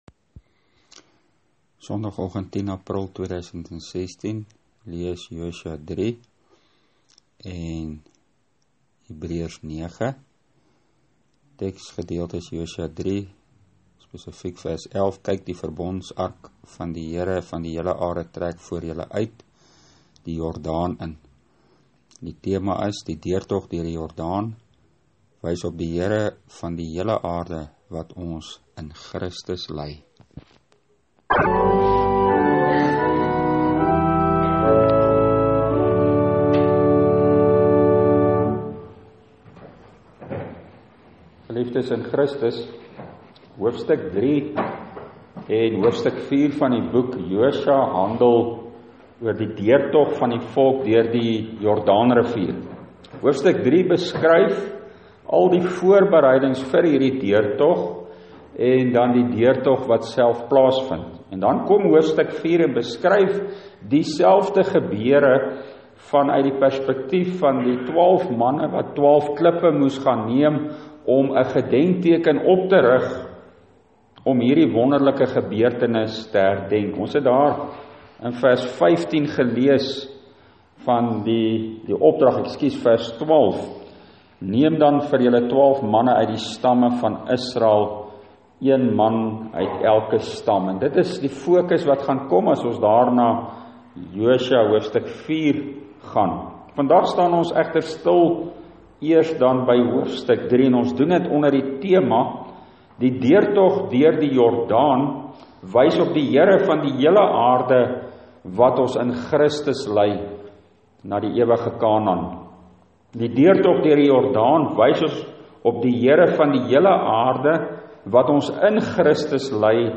Preekopname (GK Carletonville, 2016-04-10):